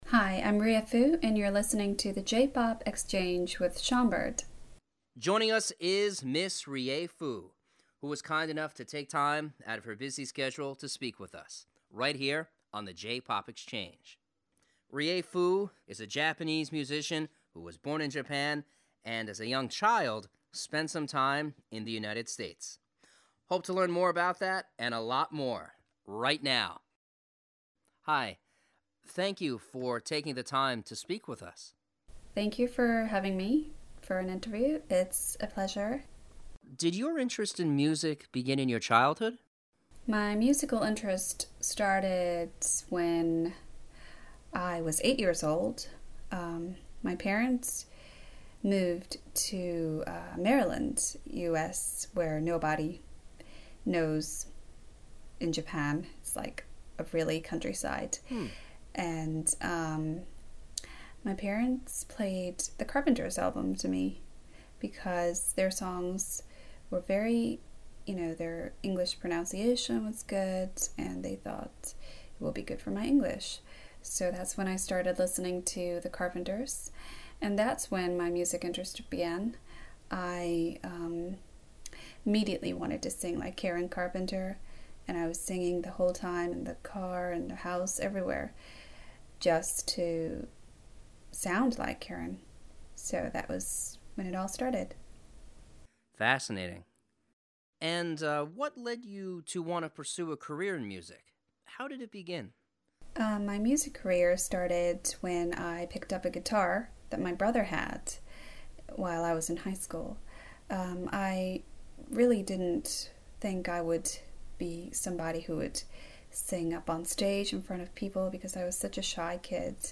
Rie Fu Listen to Radio Interview Well-respected J-Pop artist who is known for her work in Gundam Seed Destiny and recently released a new album of her renditions of songs by The Carpenters.